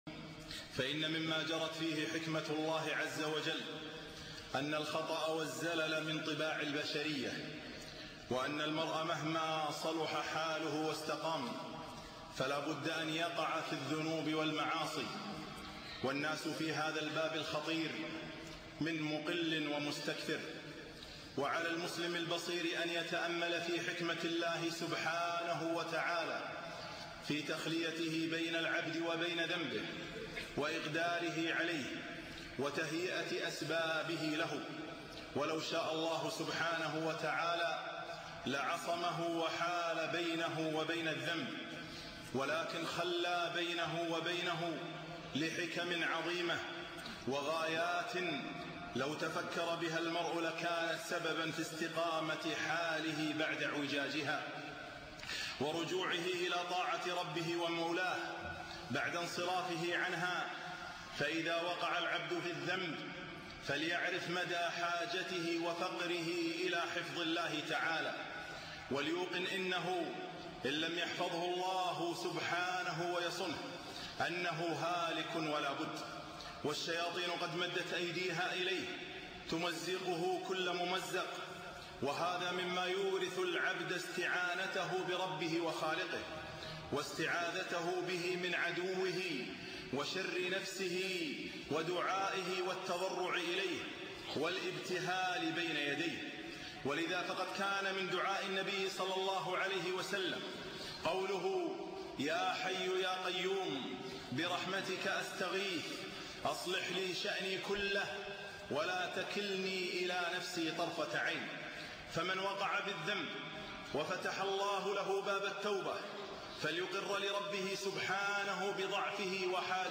خطبة - حكمة الوقوع في الذنب